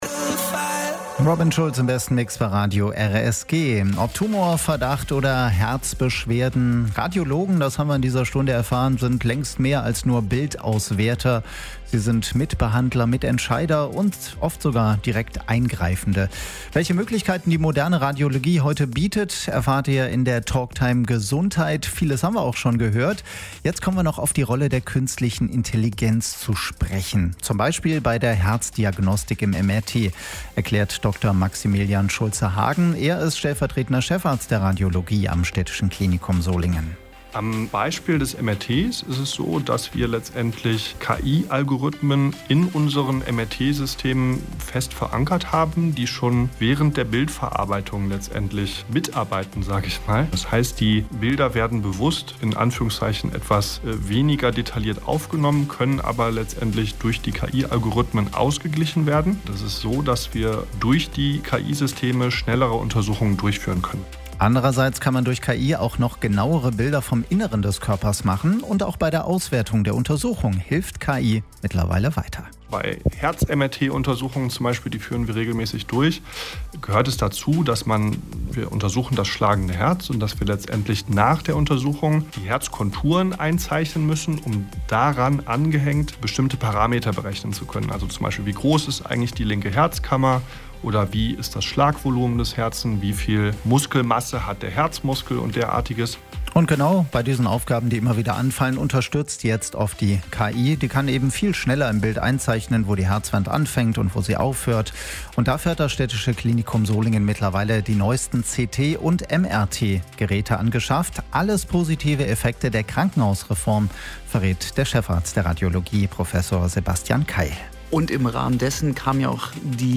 Die Radiosprechstunde lief am Samstag, 25. Juli 2025, von 12 bis 13 Uhr bei Radio RSG und kann hier nachgehört werden.